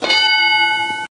correct-answer-2261_EEXRMRI.mp3